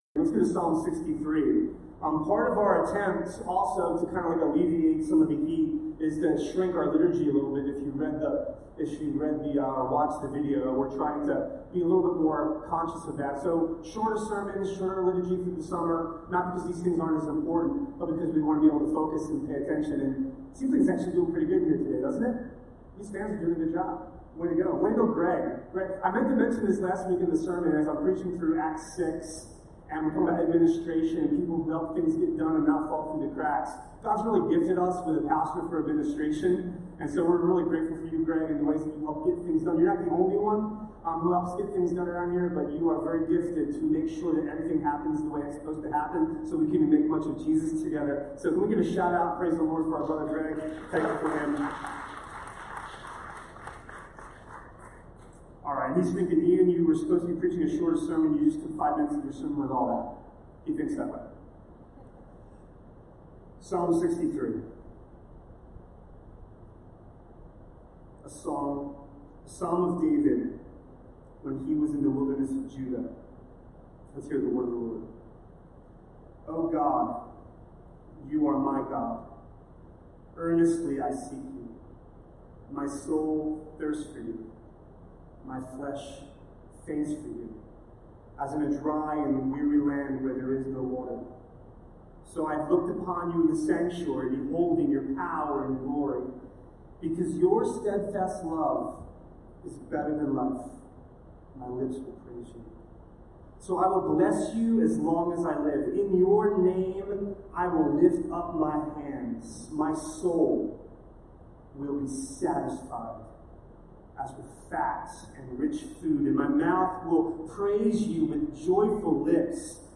A sermon from the series "The Psalms." Psalm 130 is written out of the depths, where we can find ourselves believing that we're alone.